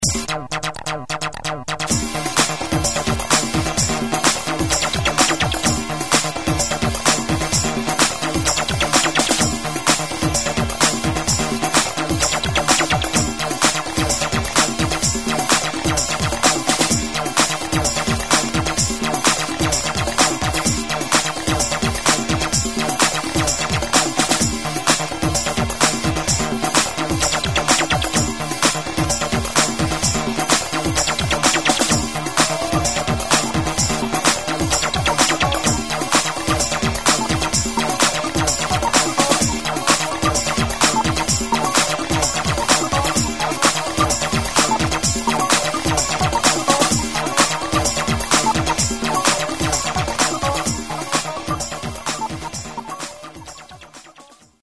*Recorded Somewhere in Kobe.